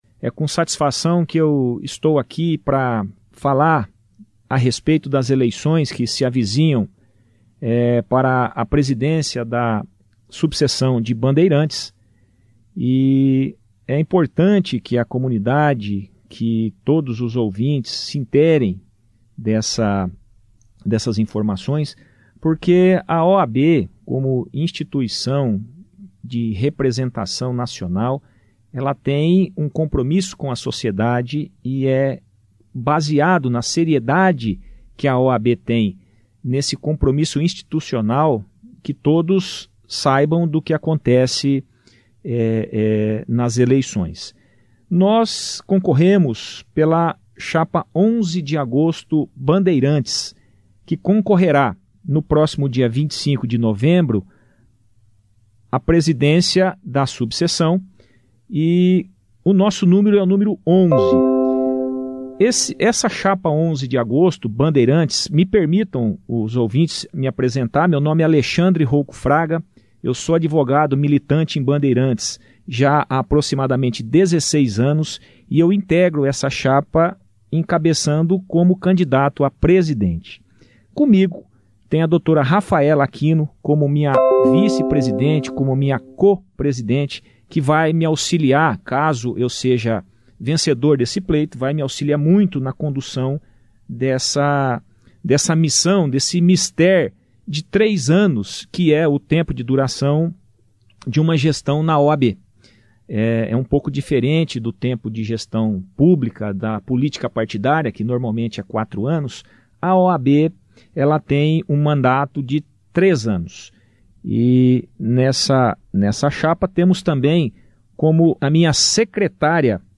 Os dois candidatos tiveram espação na edição desta quarta-feira, 24/11, no jornal Operação Cidade onde puderam com o tempo de até 10 minutos cada expor suas plataformas de trabalho à frente da entidade.